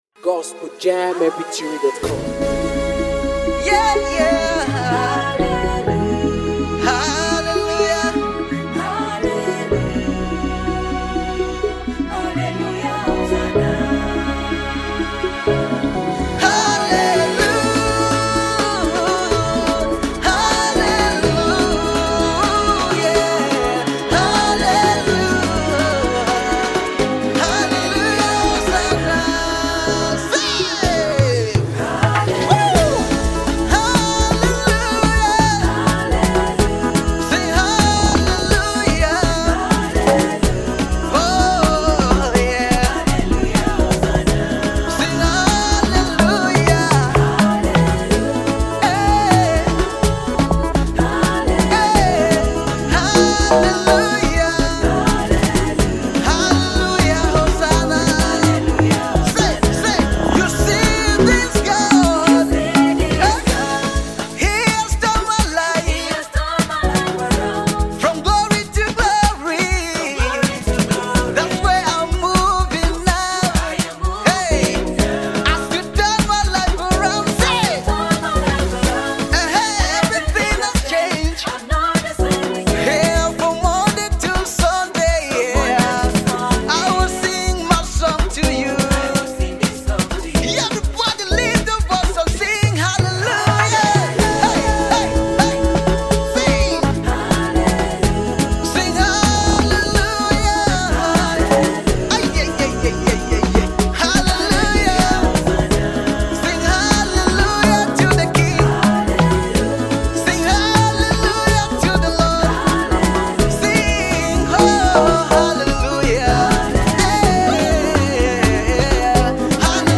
A Nigerian gospel music minister